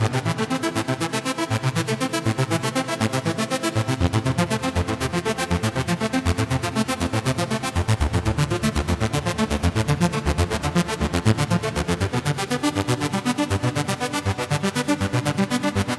描述：铁杆技术电子介绍/循环合成器